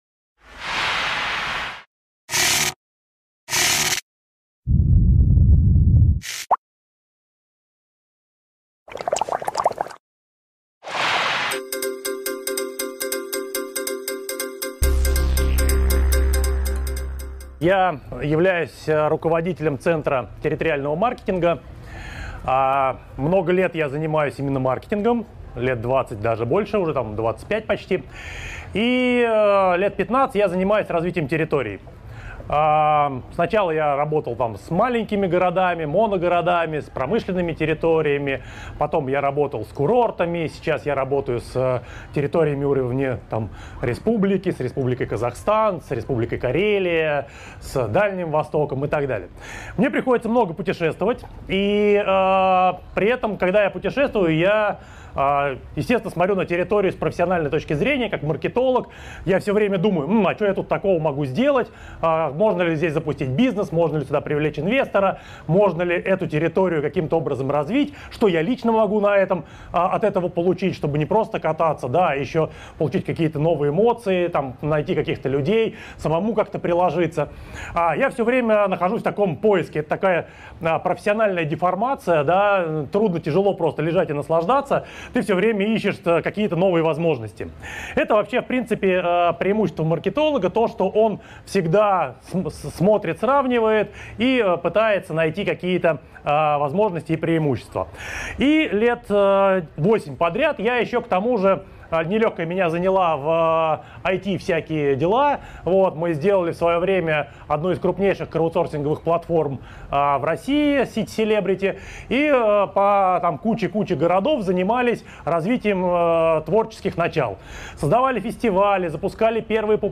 Аудиокнига Креативный туризм, или Как заработать на своем таланте и путешествиях | Библиотека аудиокниг